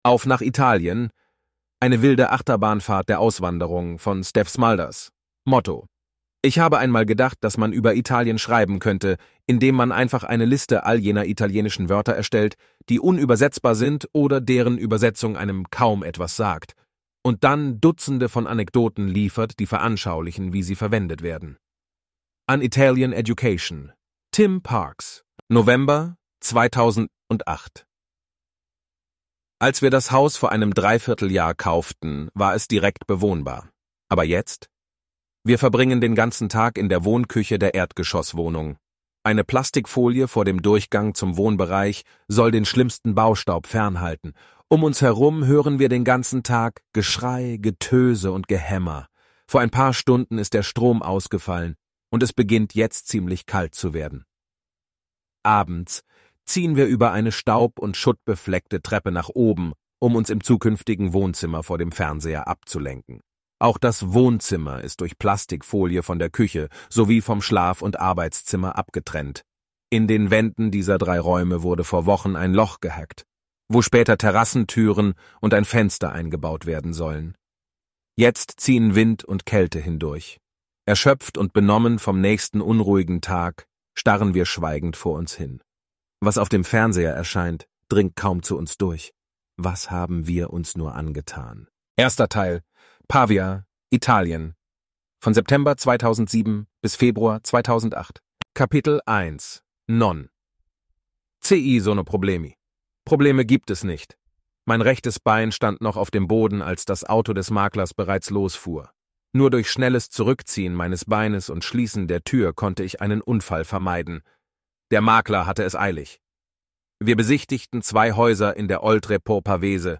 HORPROBE
horprobe.mp3